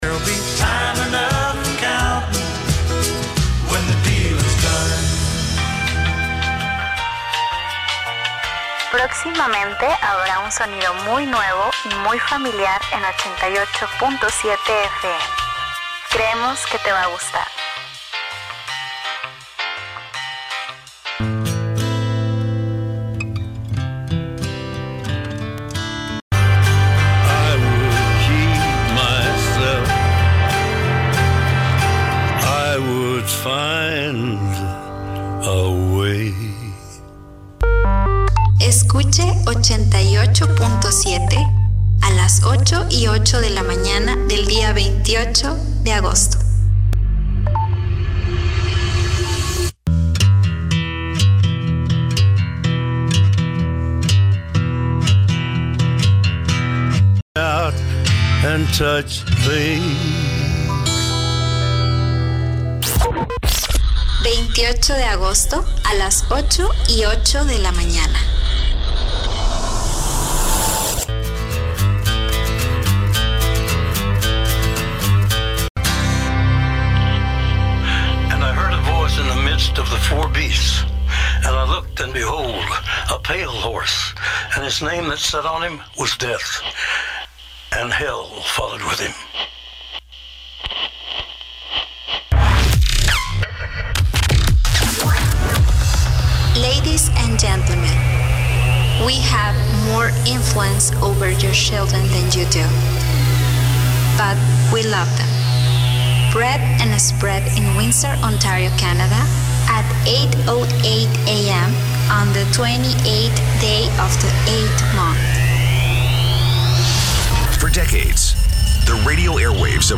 Previous Format: Country “Pure Country 89
New Format: Alternative “89X